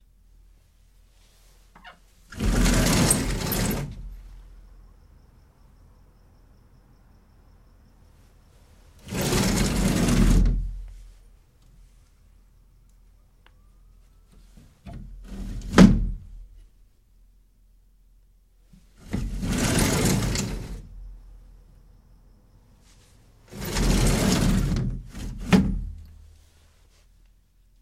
描述：puerta corredizadelbaño。推拉门的洗澡
Tag: 滑动 洗澡 O corrediza BA